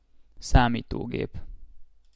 Ääntäminen
France: IPA: /ɔʁ.di.na.tœʁ/